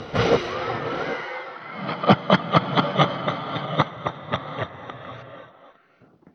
SFX - Dark Laughter
dark deep effect evil funny hall human laugh sound effect free sound royalty free Funny